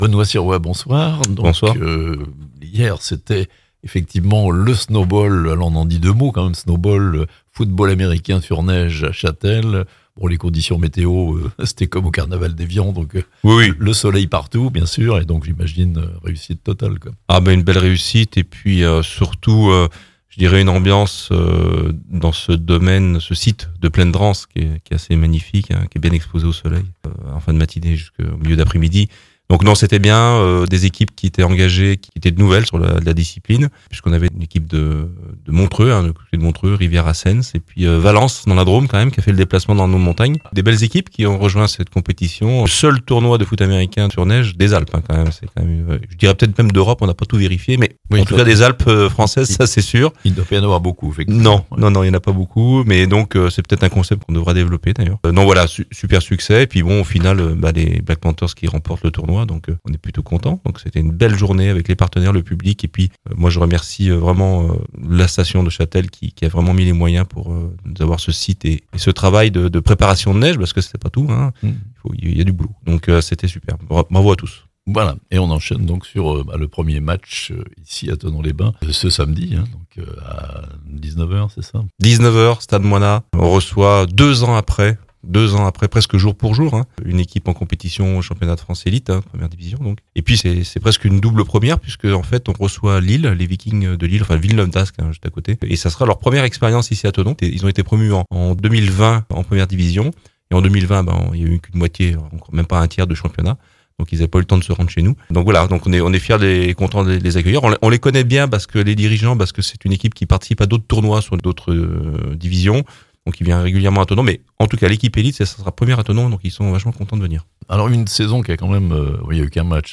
1er match à domicile de la saison pour les Black Panthers (interview)